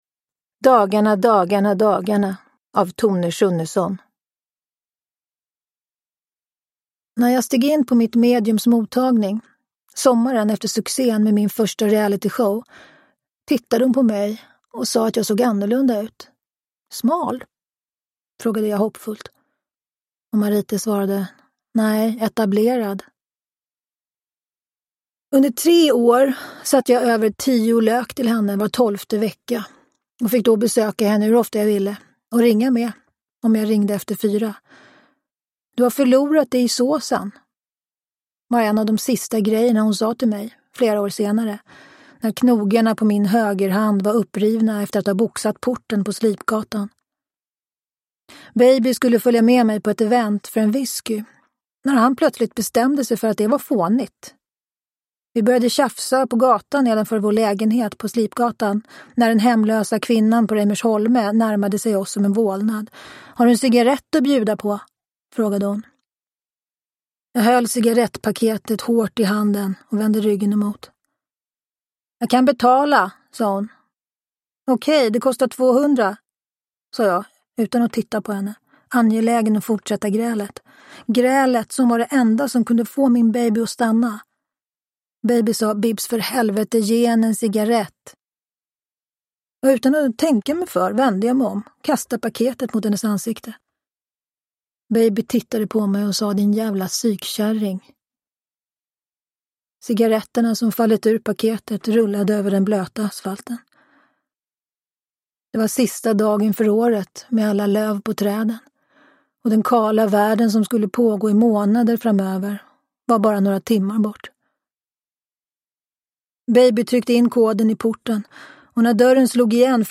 Dagarna, dagarna, dagarna – Ljudbok – Laddas ner
Uppläsare: Tova Magnusson